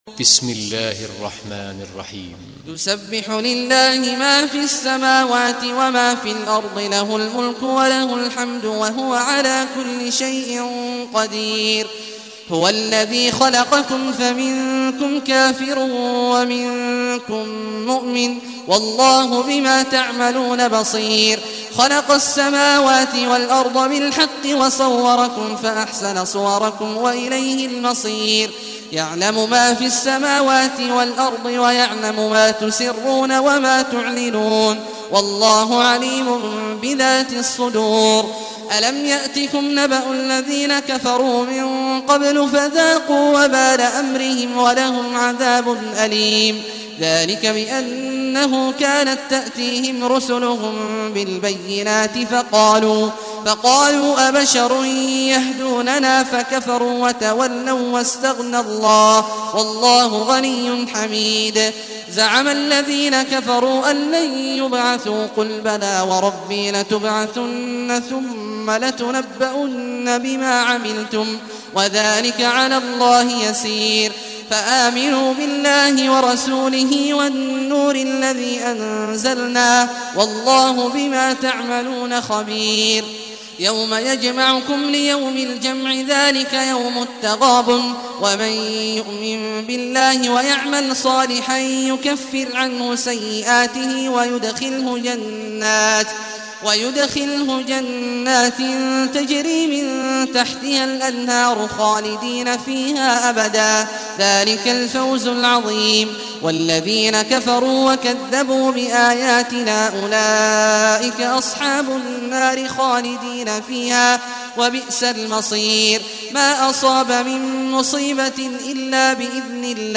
Surah Taghabun Recitation by Sheikh Awad al Juhany